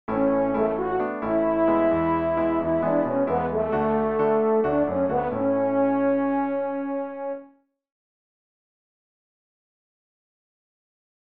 Let’s say that you’ve been working on a chorus melody, in the key of A major, and you’ve got the first 4 bars written, and don’t know what to do next.
As you can hear, it’s a simple harmonization using the I, V and IV chords: A  E  D  A